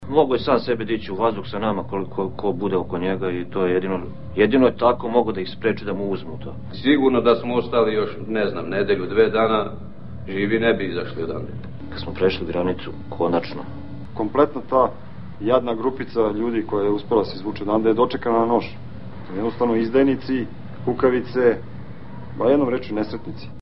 Svedočenja vojnika